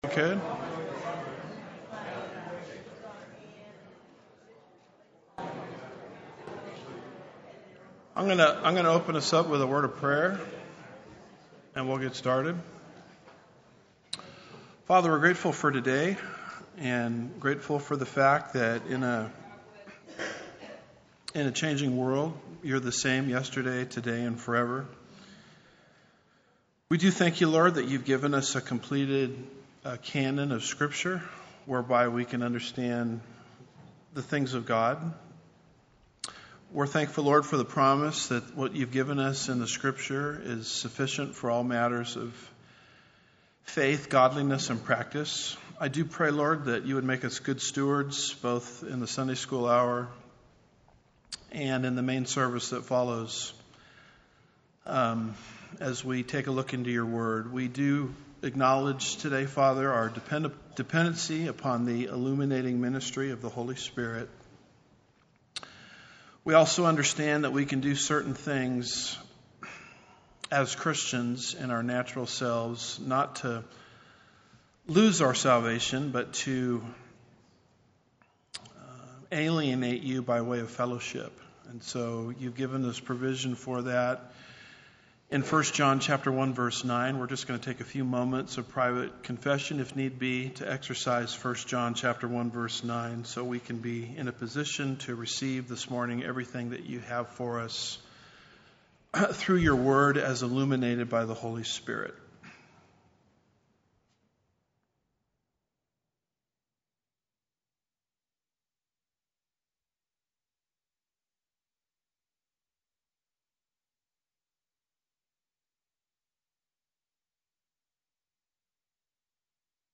Home / Sermons / Second Thessalonians 030 - Second Chances?